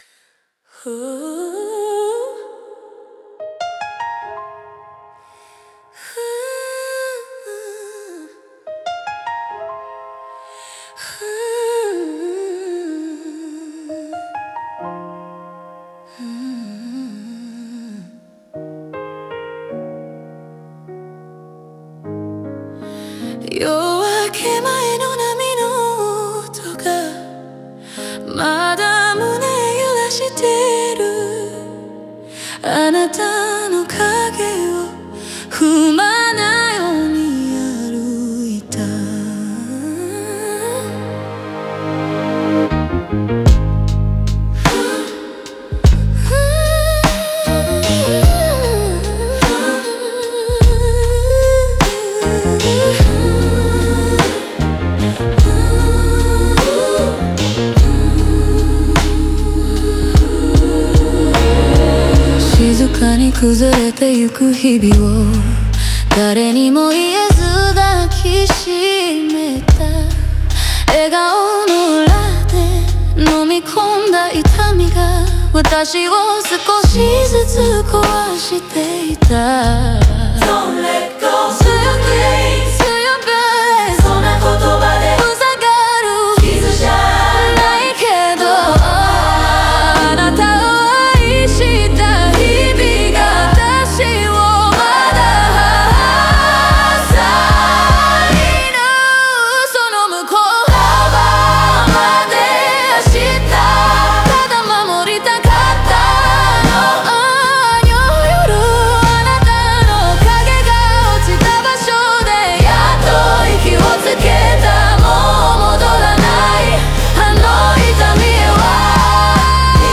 風の力強いボーカルラインと、ヒップホップ的なビートの厚みを組み合わせることで